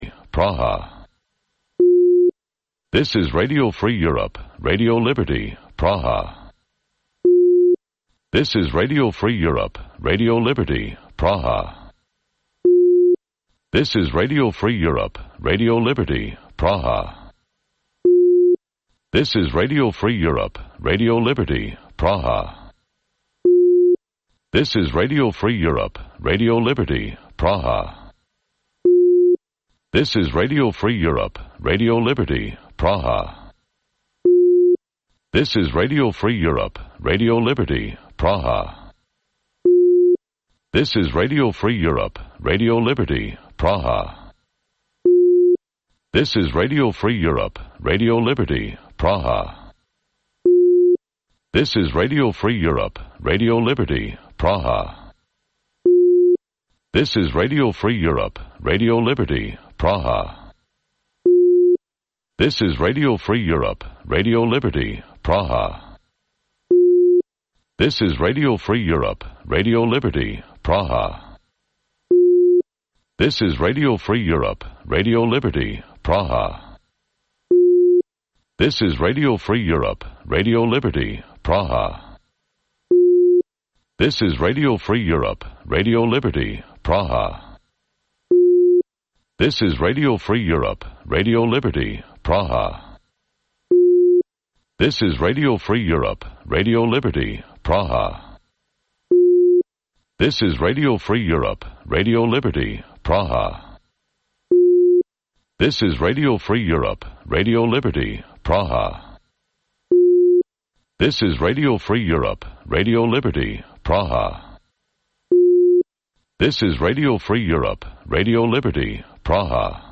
Вечірній ефір новин про події в Криму. Усе найважливіше, що сталося станом на цю годину.